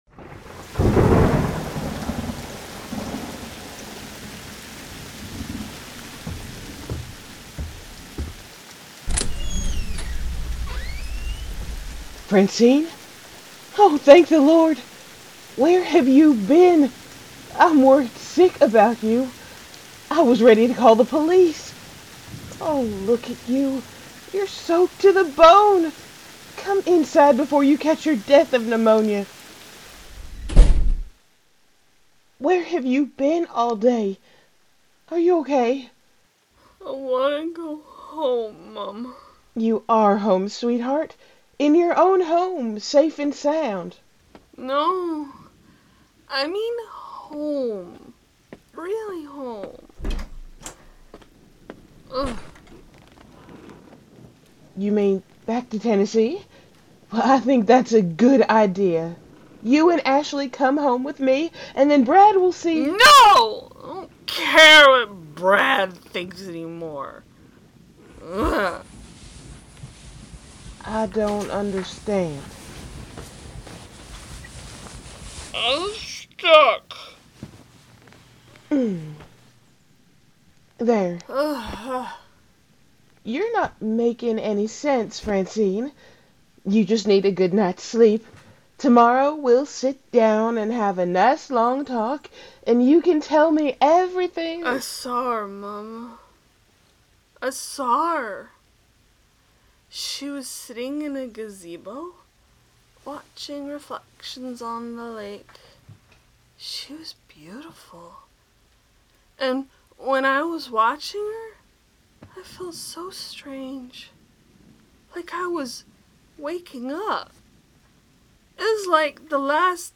Strangers In Paradise – The Audio Drama – Book 7 – Episode 2 – Two True Freaks
The Ocadecagonagon Theater Group